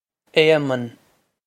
Éamonn Ay-mun
This is an approximate phonetic pronunciation of the phrase.